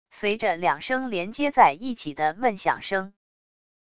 随着两声连接在一起的闷响声wav音频